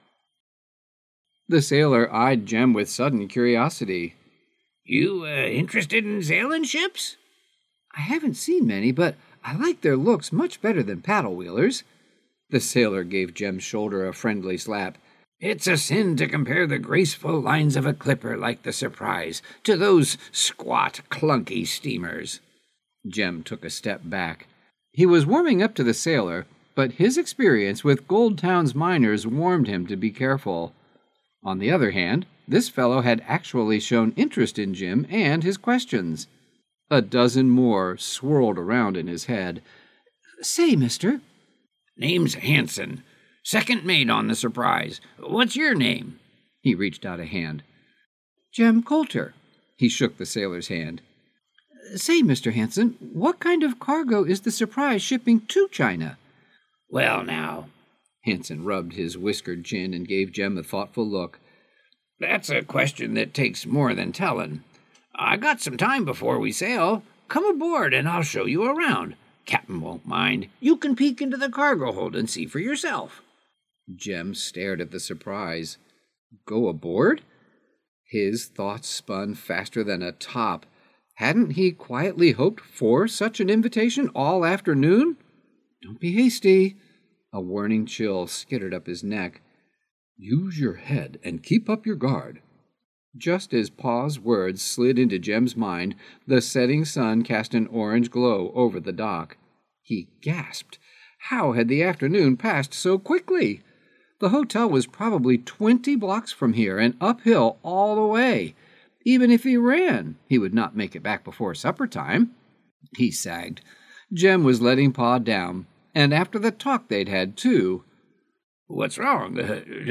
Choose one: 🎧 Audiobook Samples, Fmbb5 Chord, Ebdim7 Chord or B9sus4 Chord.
🎧 Audiobook Samples